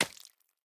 drip6.ogg